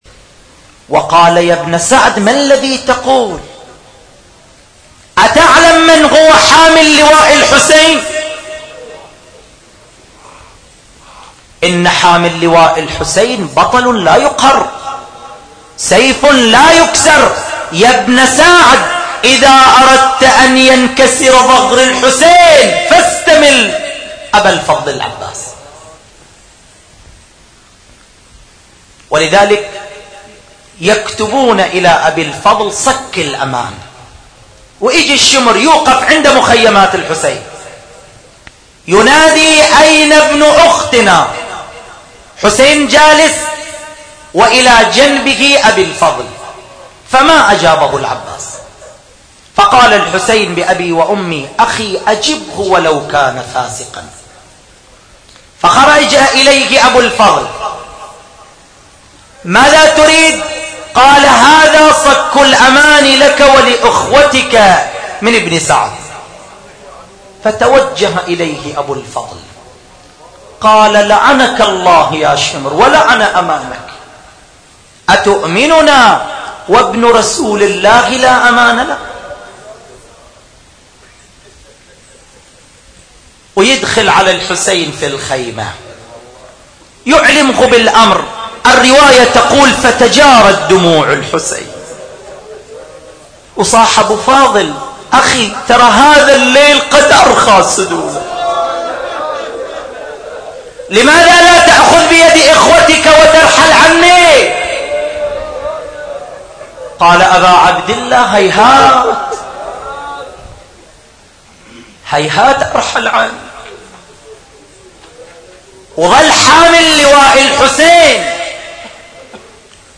نواعي حسينية7